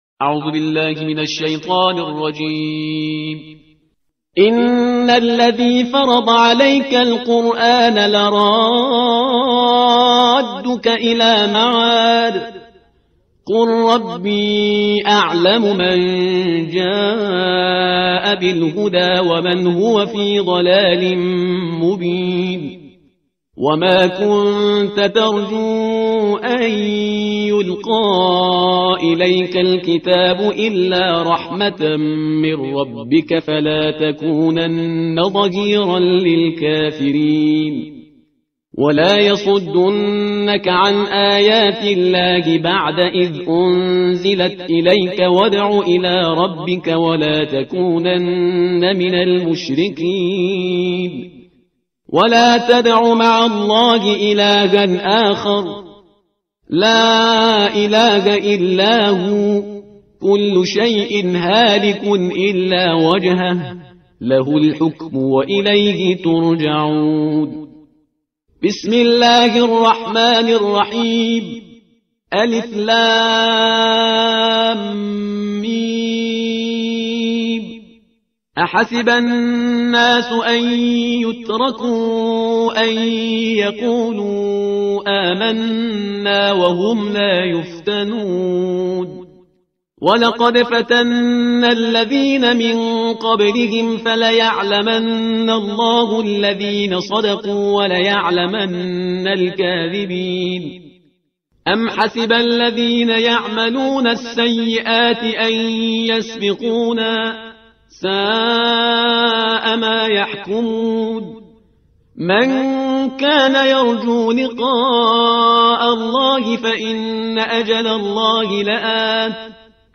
ترتیل